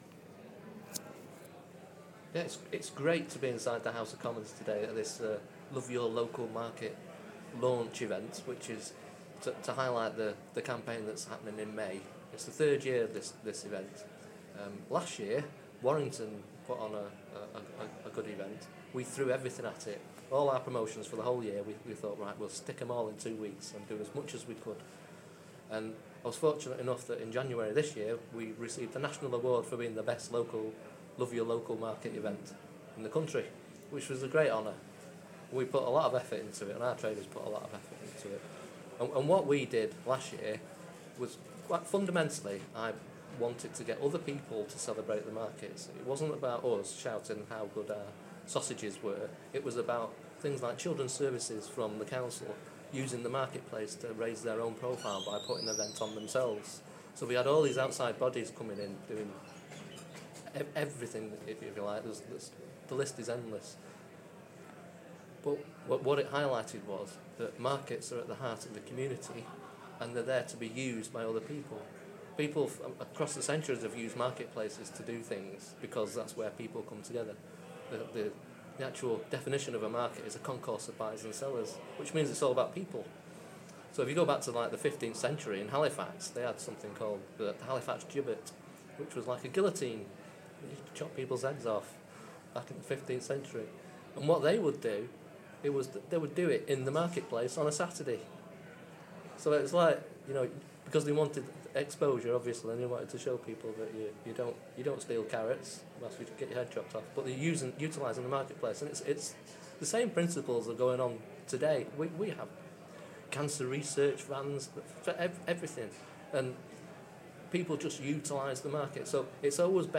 at the launch of Love Your Local Market 2014 at the Houses of Parliament.